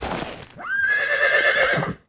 horse_special.wav